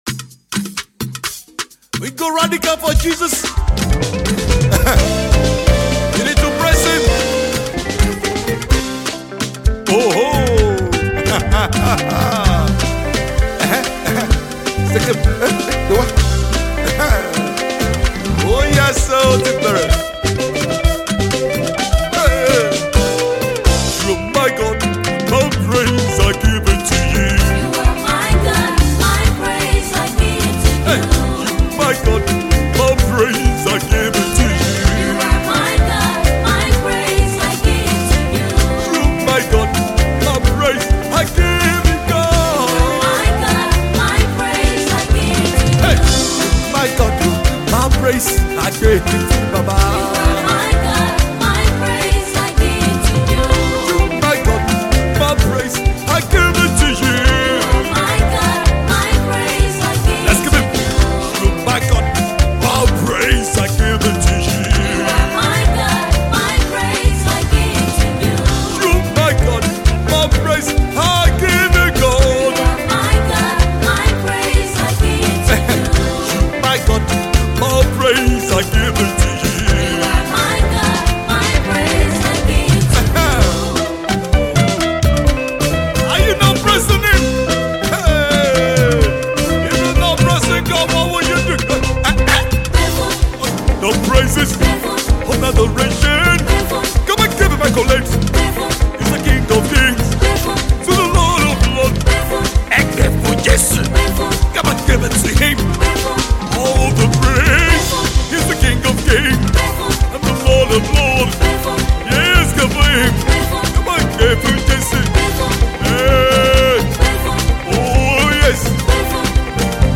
UK based Nigerian Gospel singer